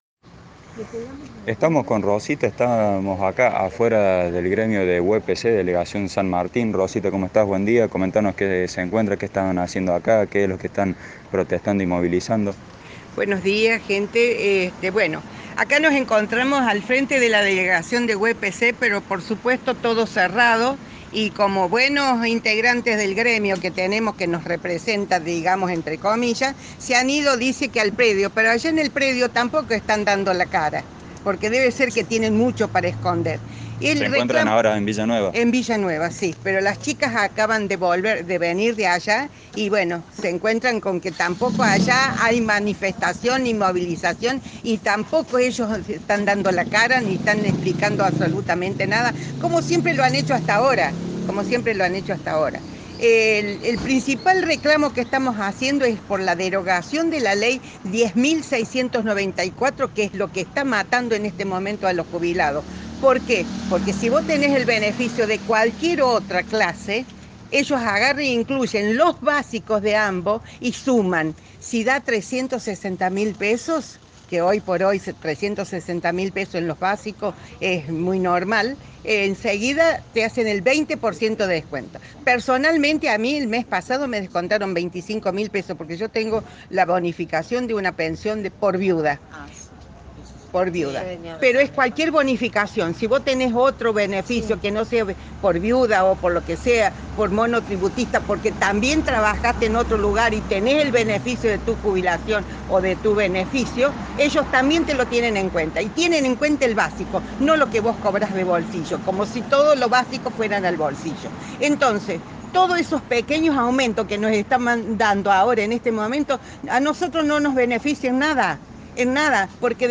AUDIO – Docentes durante el reclamo
A la bronca de los docentes se le sumó el descontento de que en el gremio nadie los atendiera. Escuche la palabra de algunos maestros ya jubilados que se movilizaron frente a la sede local de UEPC.
Docentes-jubilados-en-protesta-afuera-de-uepc.mp3